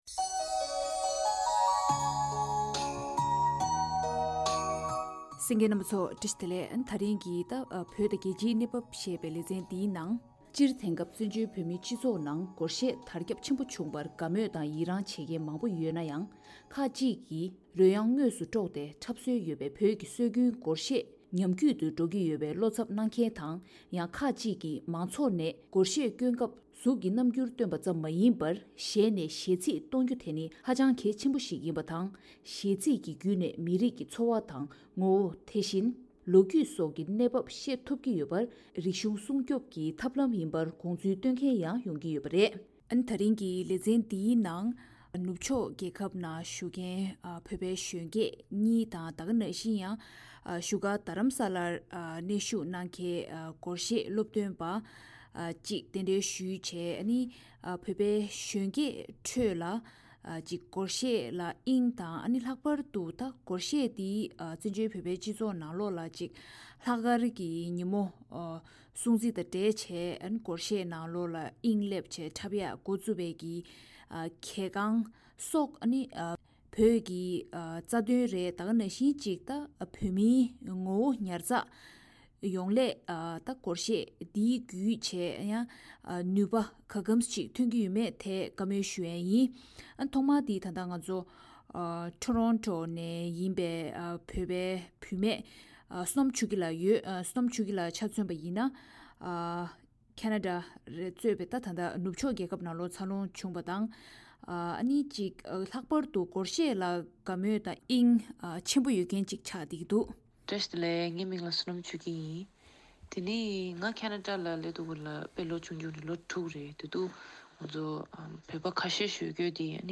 བོད་དང་རྒྱལ་སྤྱིའི་གནས་བབ་ཞེས་པའི་ལེ་ཚན་ནང་། བོད་མིའི་གཞོན་སྐྱེས་ཚོར་གླེང་མོལ་བྱེད་སྐབས།